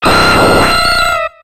Cri de Ptéra dans Pokémon X et Y.